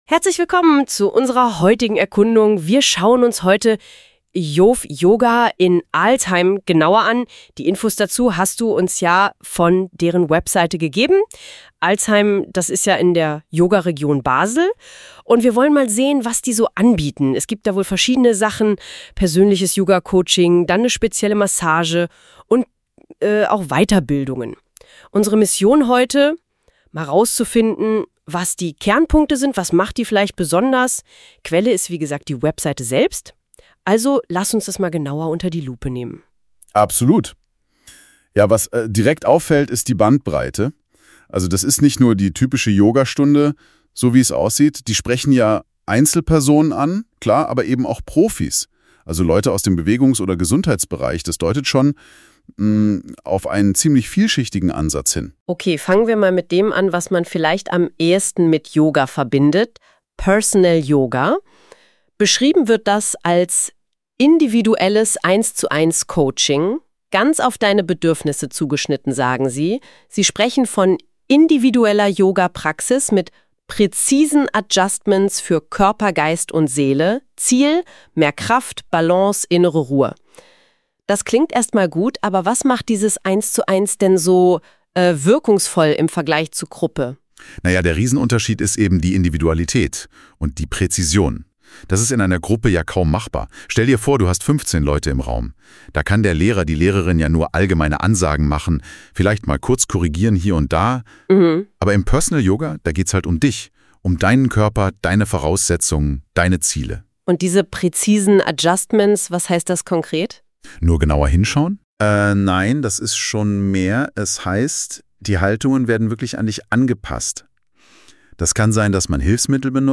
Podcast-zu-yove-yoga-KI-erstellt.mp3